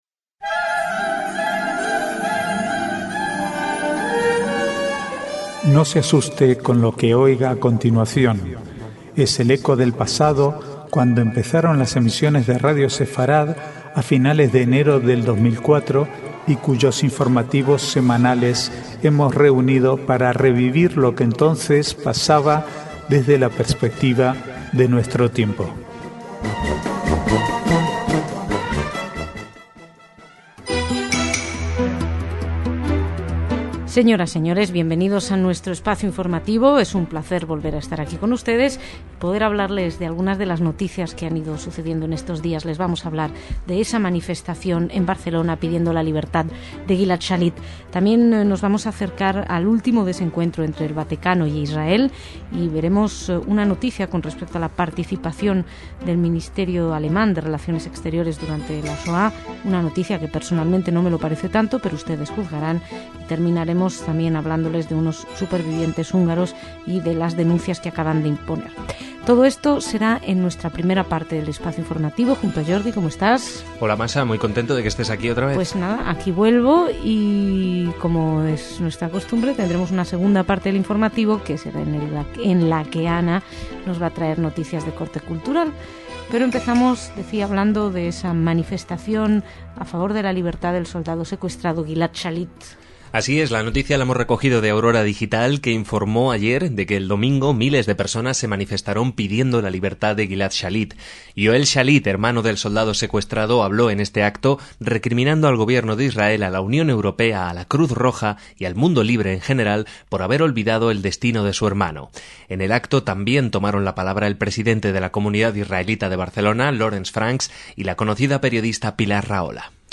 Archivo de noticias del 26 al 29/10/2010